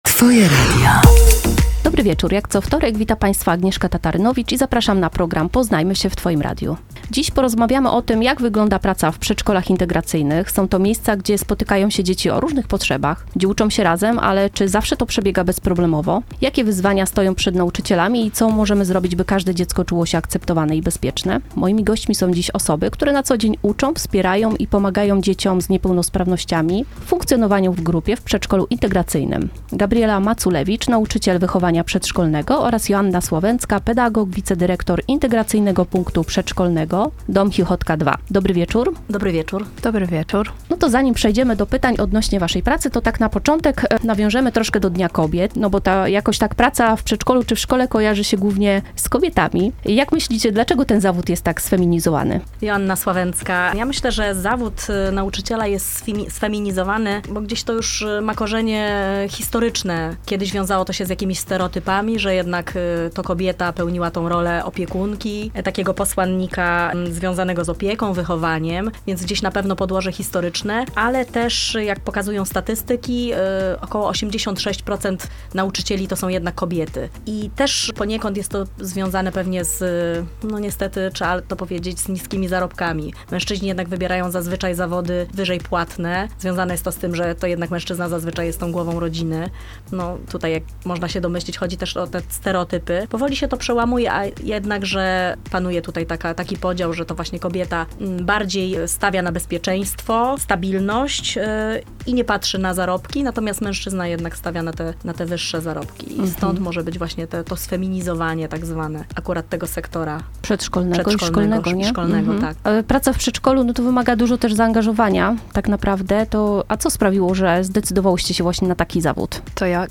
zaprasza na audycję